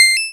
coin3.wav